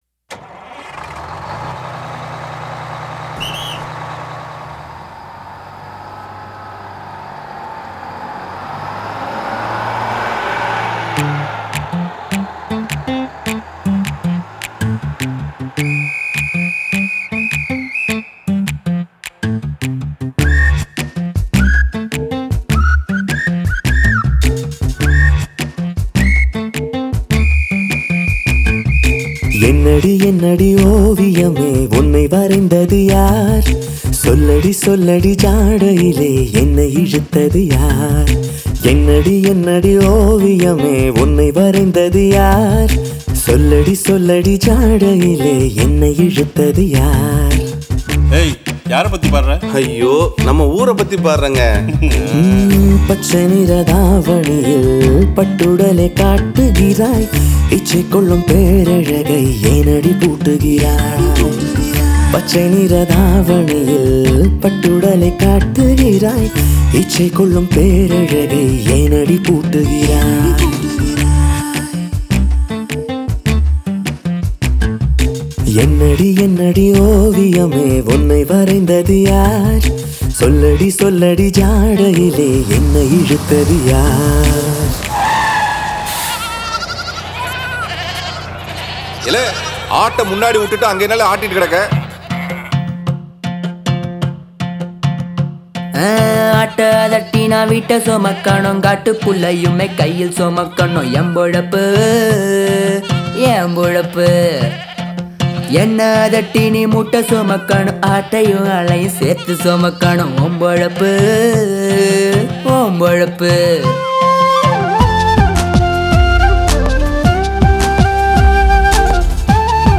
tamil mp3 songs